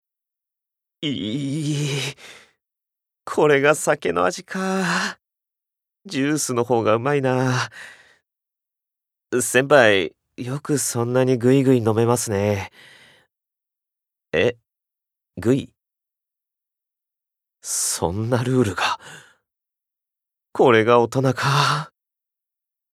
Voice Sample
ボイスサンプル
セリフ２